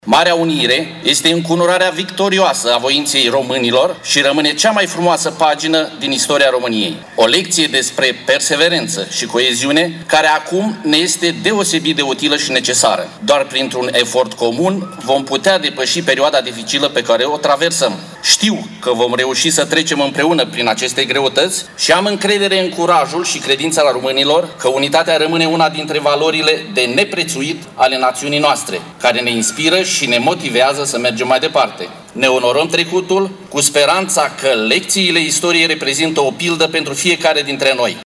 Ziua Națională a fost marcată în municipiul Suceava printr-o ceremonie restrânsă, la monumentul Bucovina Înaripată din Piaţa Drapelului.
După o slujbă religioasă, ministrul Turismului și IMM –urilor DANIEL CADARIU a prezentat mesajul Guvernului, potrivit căruia “Marea Unire a avut loc după nenumărate sacrificii umane și materiale”.